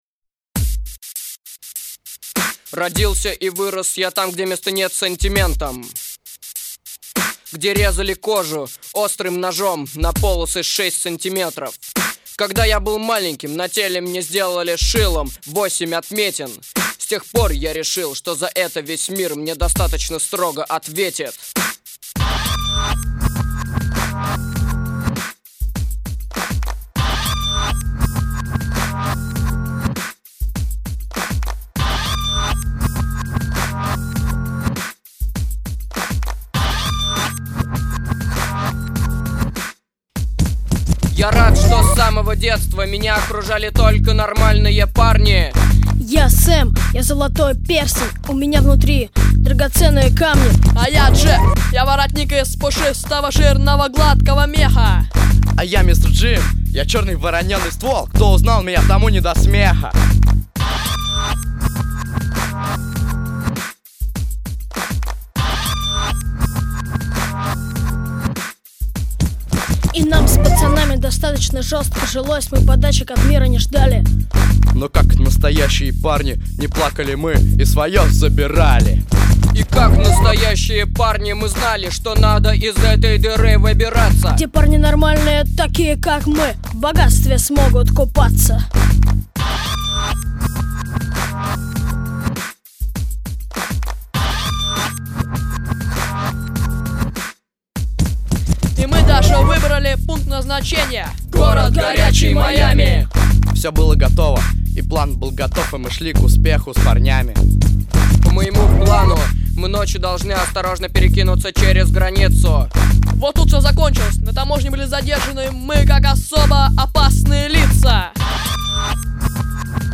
Песни исполняют: Участники спектаклей
Записано в студии Easy Rider в апреле 2008 года